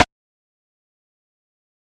Metro Mad Perc.wav